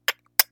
game/audio/soundeffects/door_unlock.mp3 at Development
door_unlock.mp3